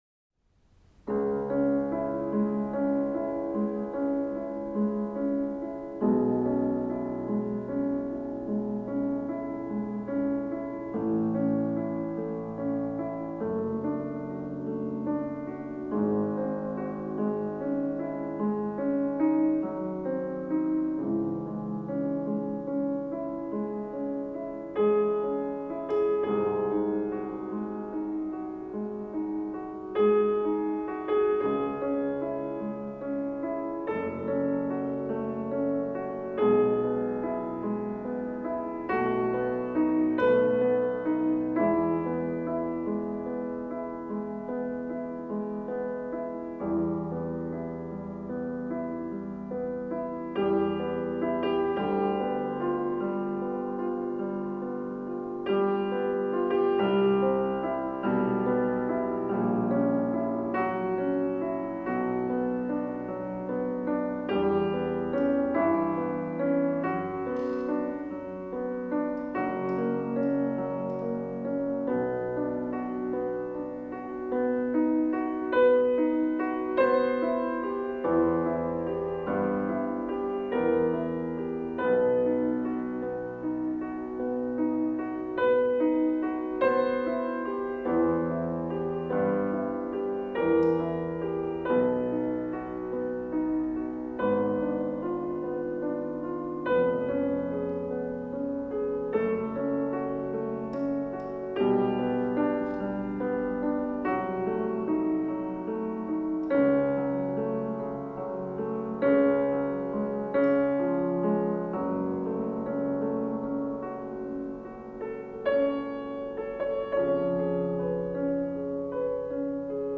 Hier nun also meine Aufnahme des ersten Satz der Mondscheinsonate auf einem damals circa dreissig Jahre alten Steinway D Flügel.
Damit habe ich einige Vorspiele und Proben aufgezeichnet. Im Konzertsaal der Hochschule standen zu dieser Zeit zwei wunderbare Steinway D Flügel.